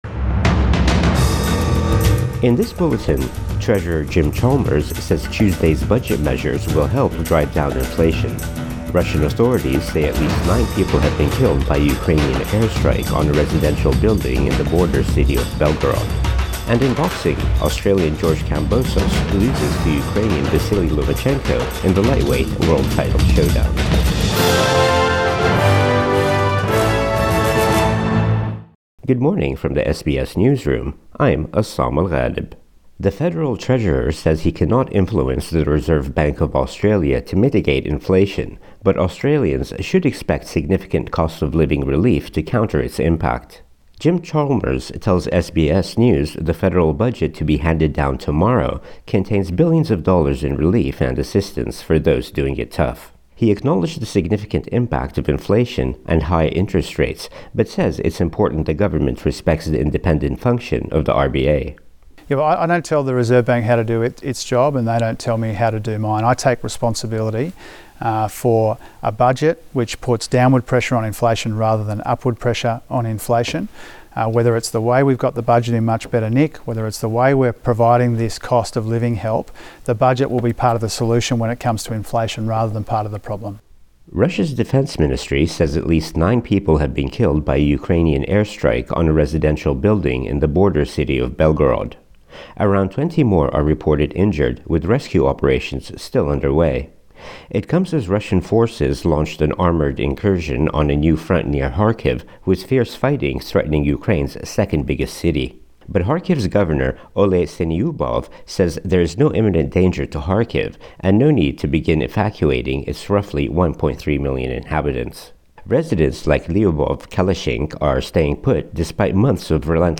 Morning News Bulletin 13 May 2024